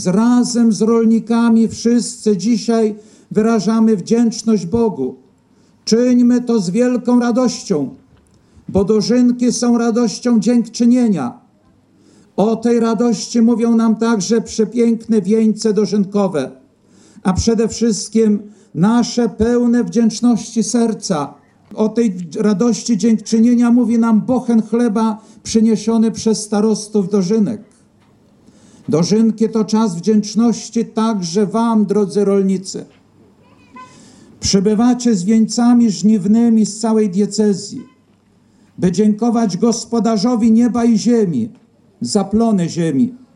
Dożynki diecezjalne odbyły się w sobotę (08.09) w Gołdapi.